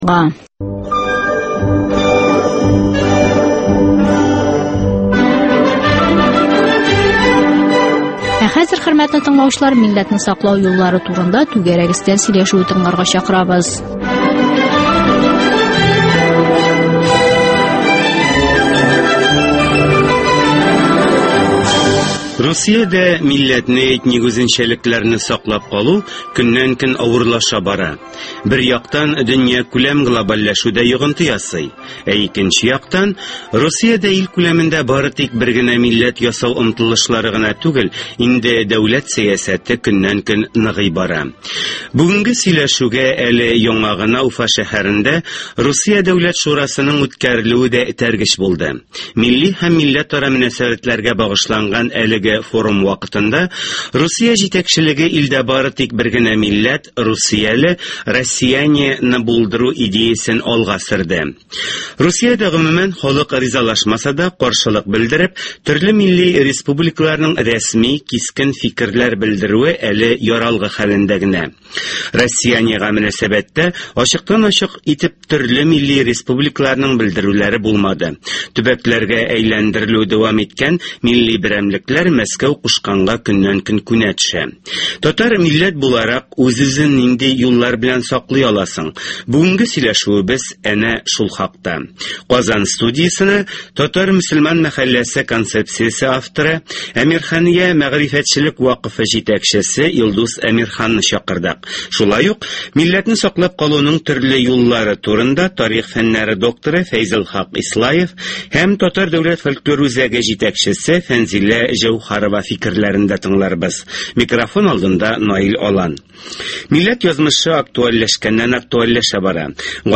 Түгәрәк өстәл сөйләшүе: Милләтне саклау юллары